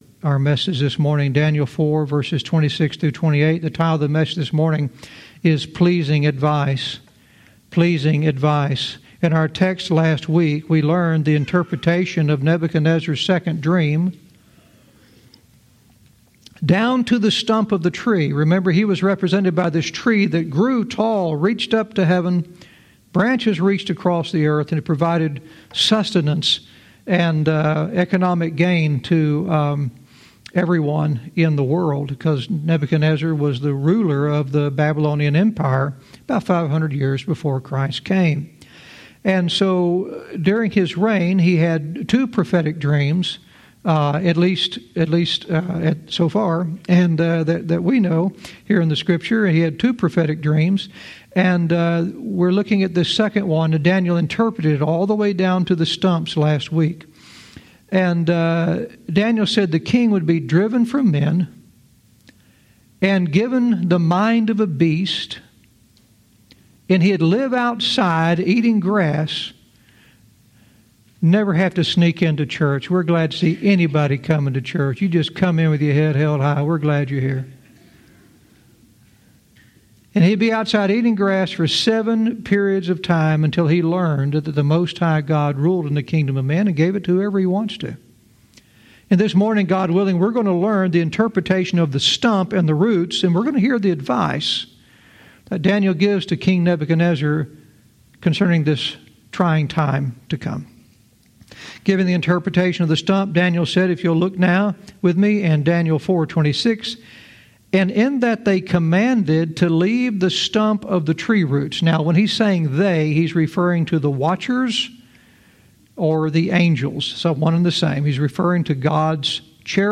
Verse by verse teaching - Daniel 4:26-28 "Pleasing Advice"